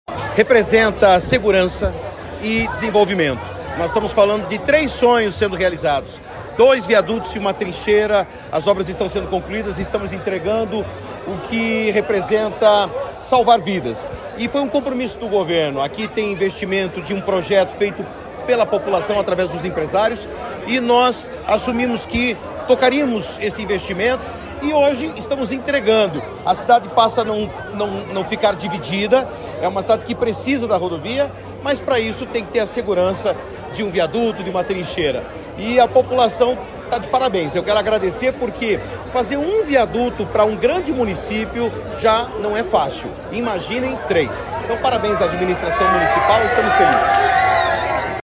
Sonora do secretário da Infraestrutura e Logística, Sandro Alex, sobre a inauguração de viaduto na PR-444 em Mandaguari